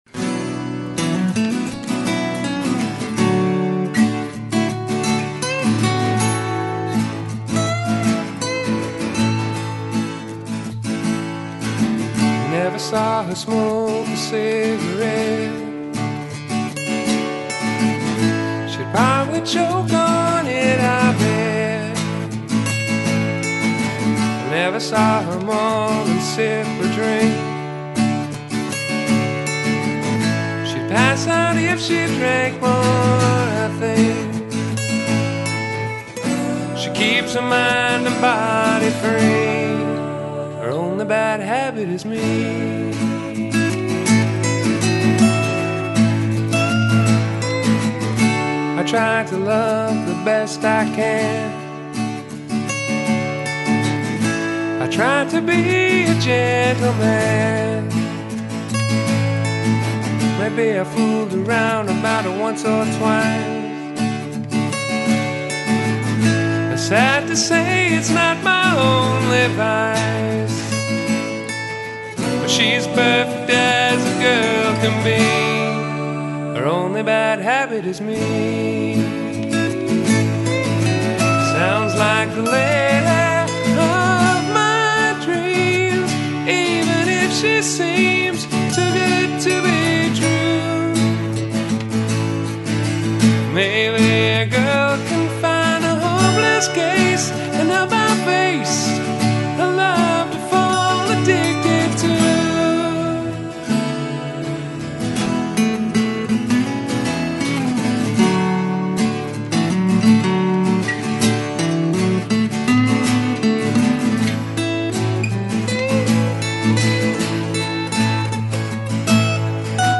ACOUSTIC DEMOS
Here are some of the rough demo's for the album.